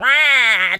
duck_quack_hurt_06.wav